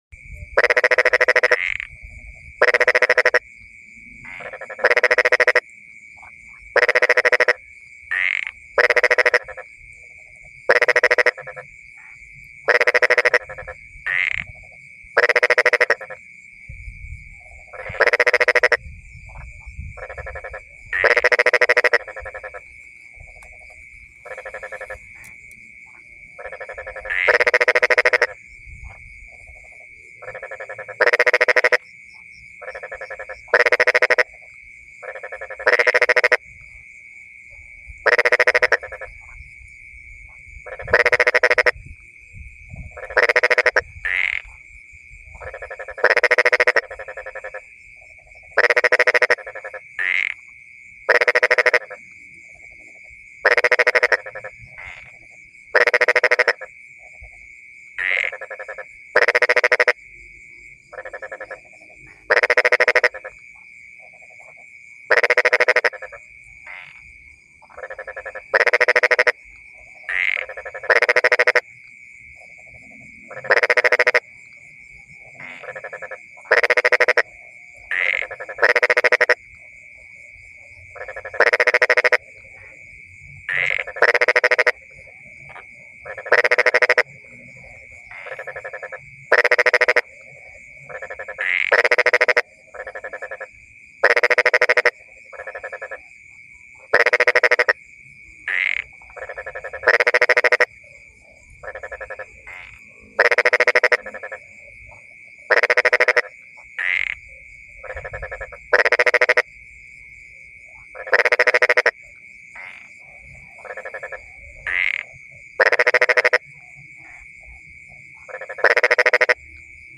Suara kodok ijo ngorek, Suara kodok sawah (kodok ijo), di malam hari, Marsh frog sound, Binatang amfibi yang hidup di sawah, selokan, & rawa, Fejervarya cancrivora, (kodok ijo, kodok cina, bangkong dingdang).
Suara Kodok Ijo ngorek
Kategori: Suara binatang liar
suara-kodok-ijo-ngorek-id-www_tiengdong_com.mp3